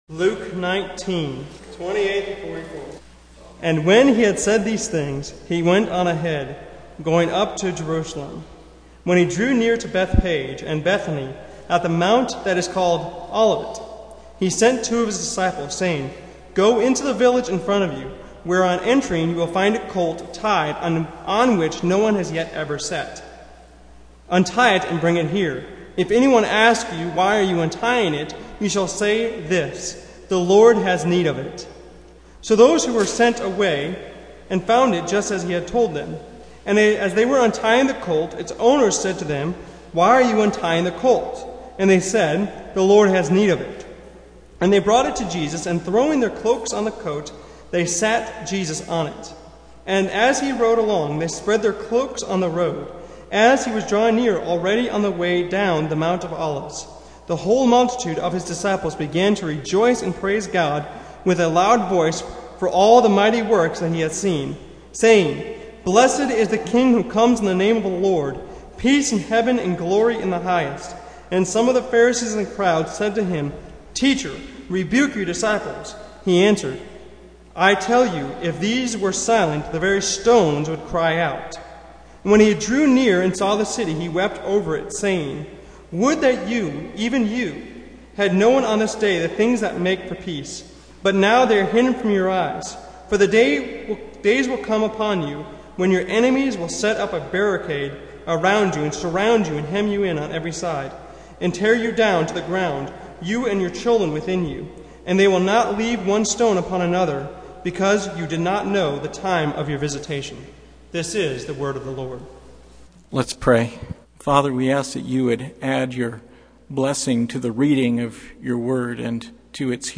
Passage: Luke 19:28-44 Service Type: Sunday Morning